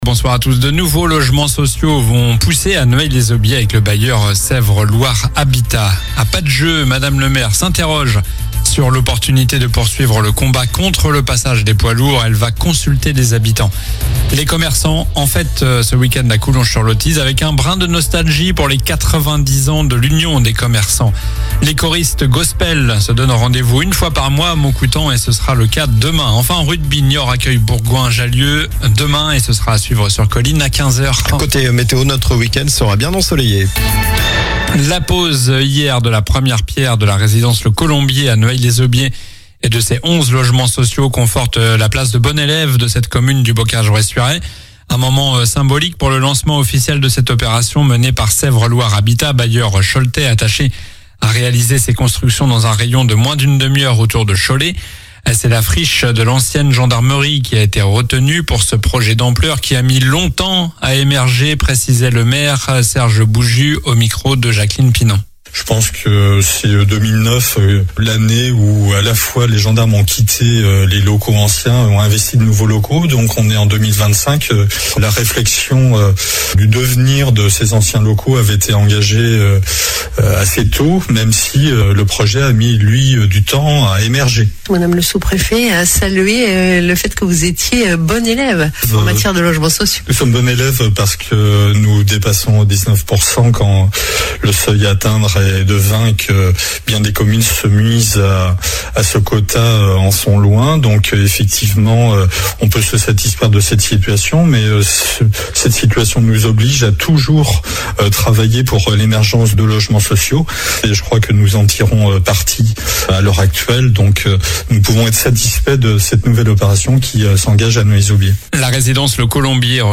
Journal du vendredi 10 octobre (soir)